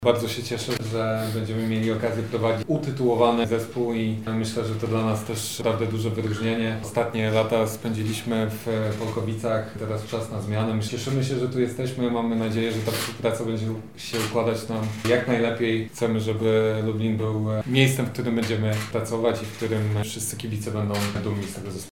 Mówił na konferencji prasowej nowy szkoleniowiec.